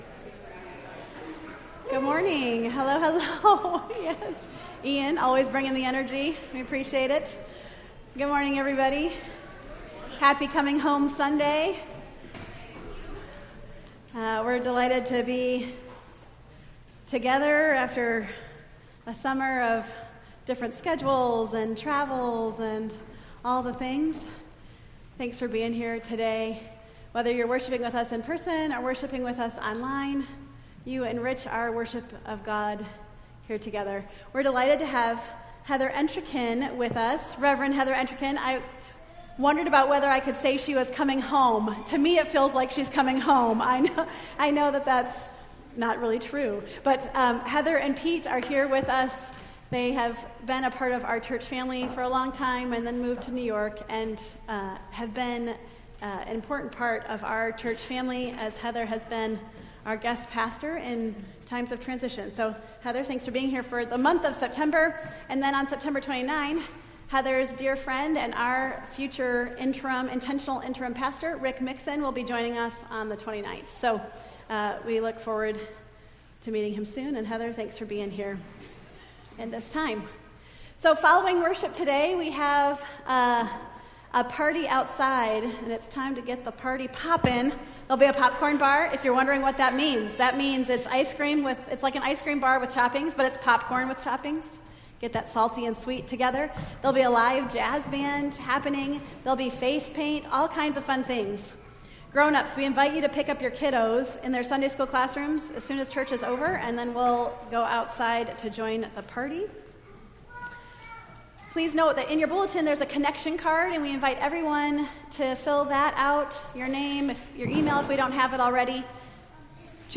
Entire September 8th Service